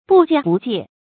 不间不界 bù jiān bù jiè
不间不界发音
成语注音 ㄅㄨˋ ㄐㄧㄢ ㄅㄨˋ ㄐㄧㄝ ˋ